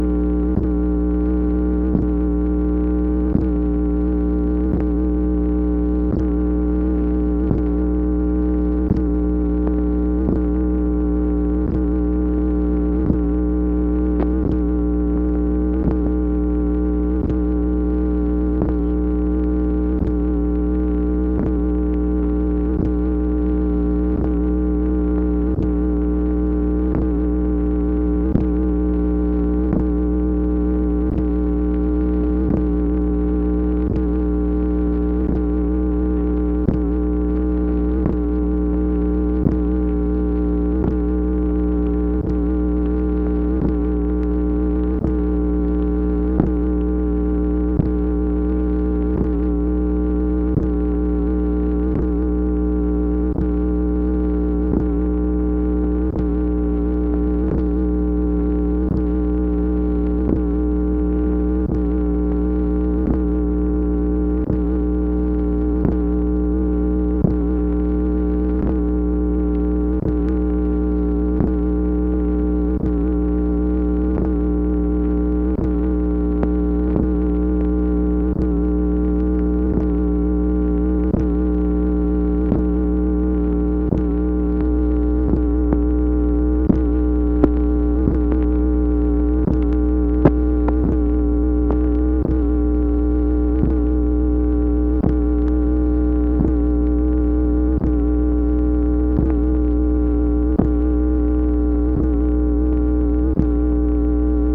MACHINE NOISE, February 12, 1964
Secret White House Tapes | Lyndon B. Johnson Presidency